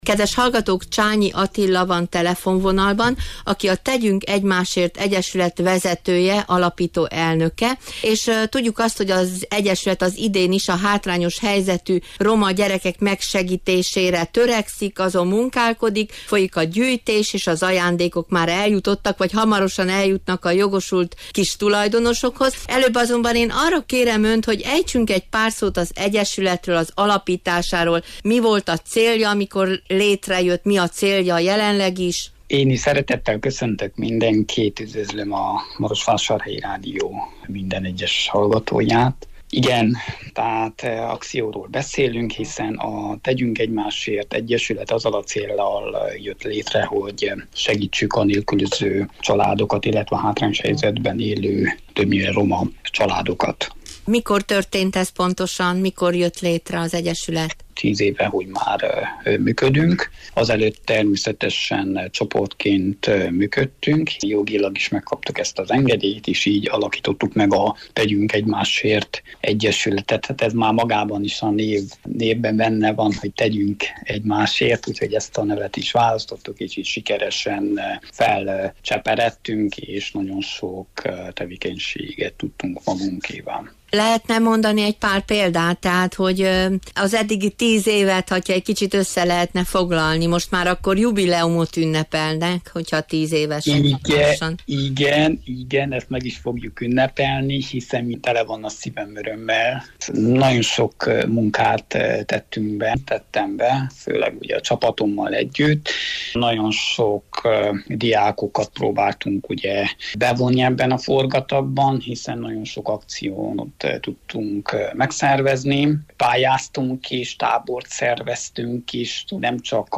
szombaton kerestük meg telefonon